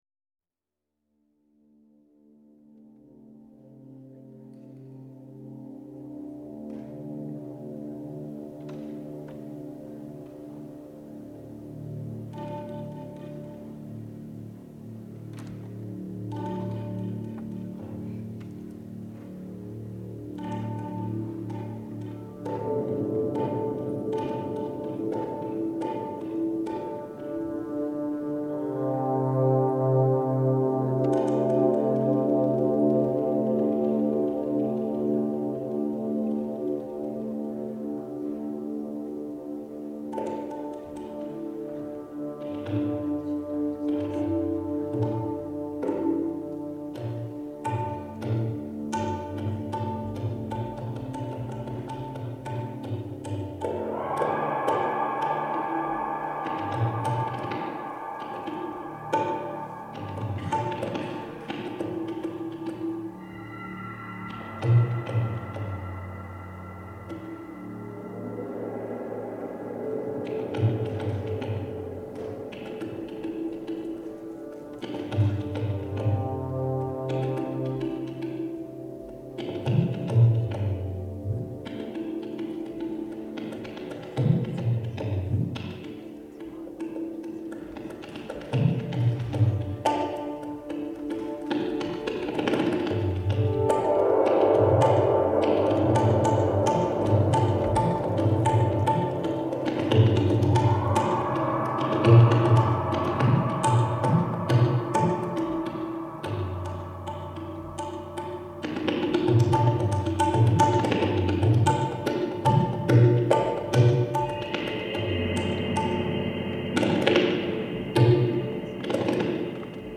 Live at the South Church 12/1/01
tabla solo